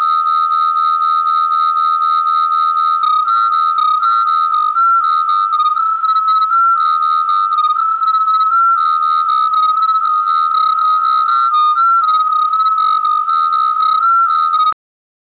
THROB é baseado em pares de tons com alguns caracteres baseados em apenas um tom. Foi definido como sistema de "2 de 8 +1 tom", ou mais simplesmente, é baseado na descodificação de um par de tons dentro de uma gama de 9 existentes.
throb.wav